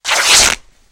the-sound-of-tearing-cloth